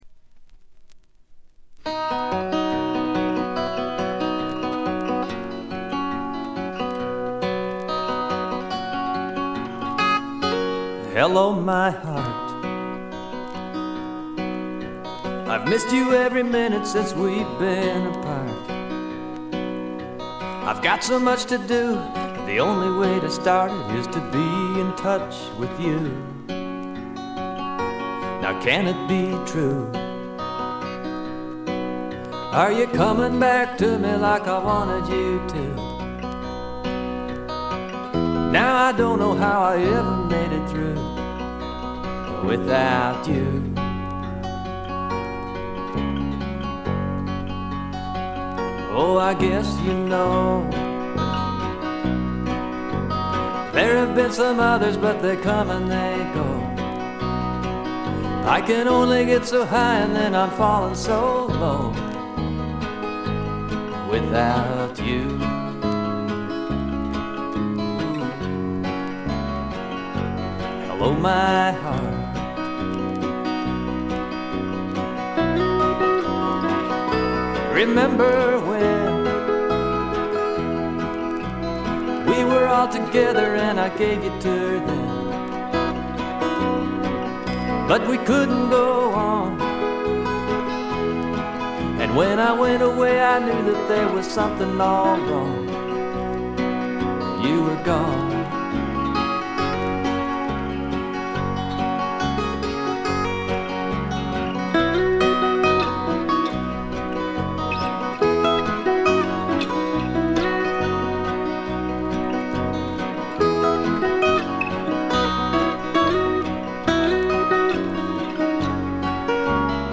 部分試聴ですがほとんどノイズ感無し。
さて内容は85年という時代を感じさせないフォーキーな好盤に仕上がっております。
試聴曲は現品からの取り込み音源です。